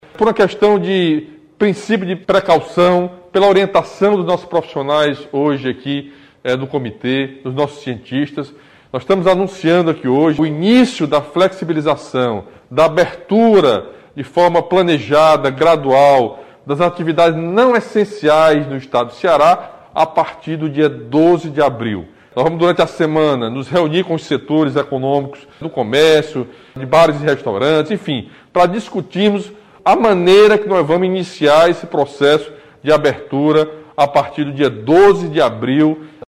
A decisão foi tomada pelo Comitê Estadual de Enfrentamento à Pandemia do Coronavírus no Ceará em reunião no fim da tarde deste domingo (4), e anunciada pelo governador Camilo Santana, acompanhado pelo prefeito de Fortaleza, José Sarto, em transmissão ao vivo pelas redes sociais.